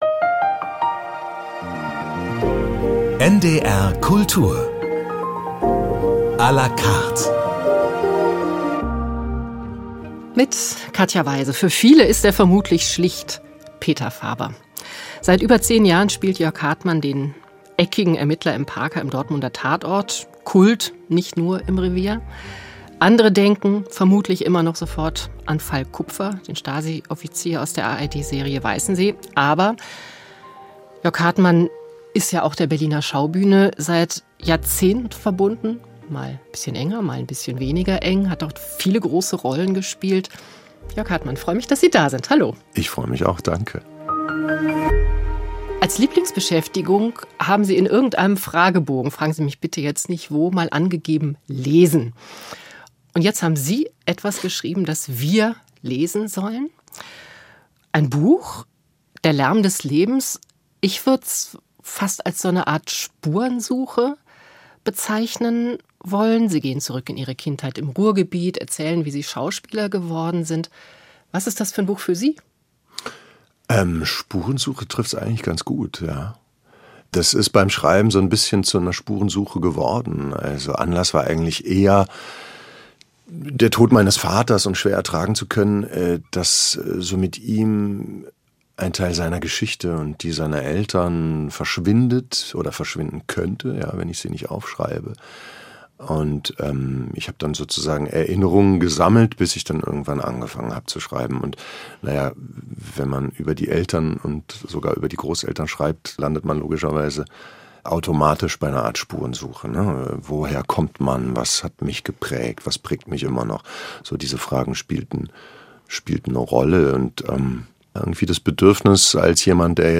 Der Schauspieler Jörg Hartmann erzählt sein Leben - 18.03.2024